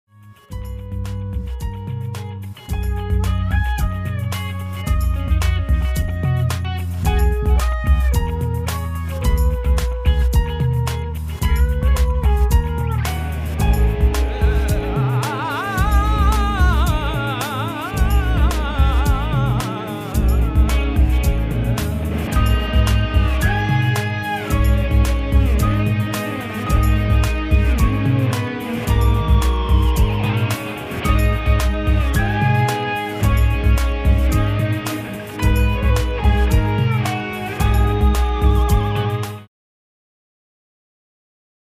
studio in Karachi May '05